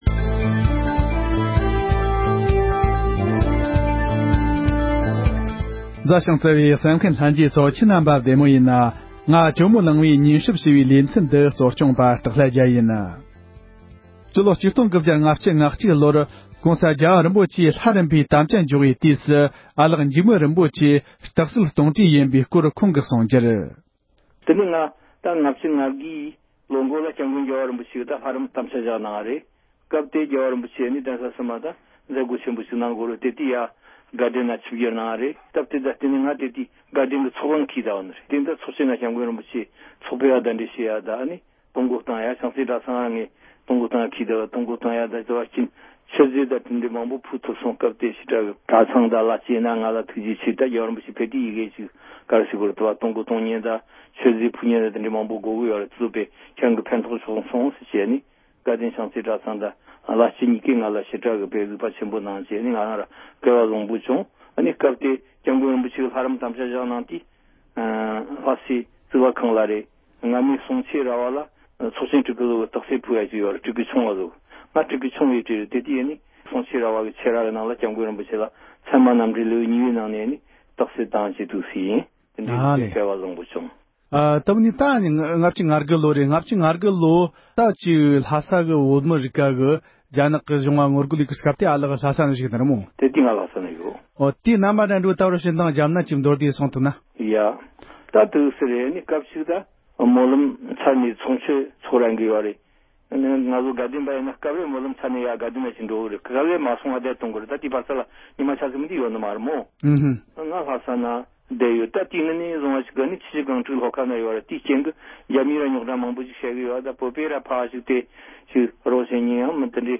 བཀའ་ཟུར་ཨ་ལགས་འཇིགས་མེད་རིན་པོ་ཆེ་མཆོག་གི་སྐུ་ཚེའི་ལོ་རྒྱུས་སྐོར་ལ་བཅར་འདྲི་ཞུས་པའི་དུམ་བུ་གཉིས་པ།